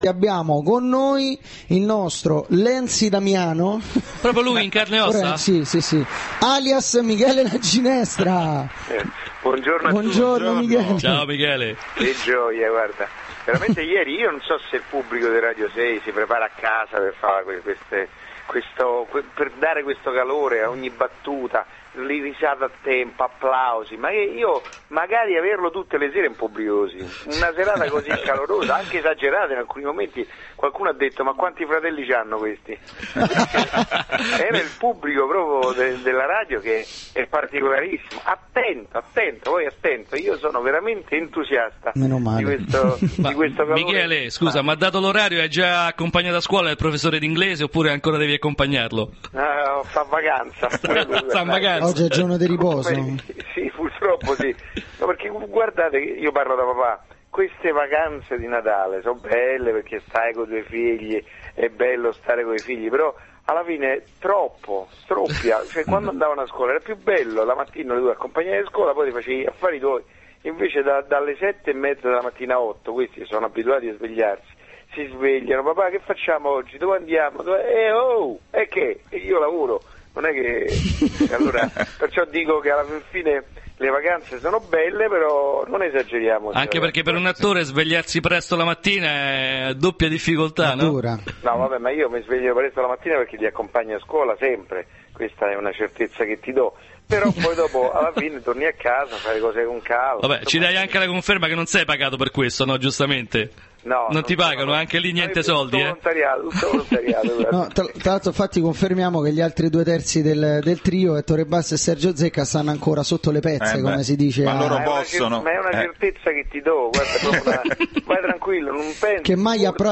Intervento telefonico Michele La Ginestra